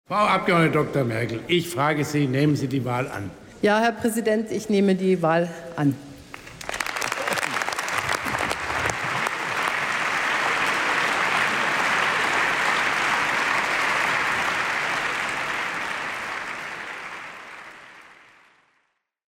14-mar-ambianta-alegere-Merkel.mp3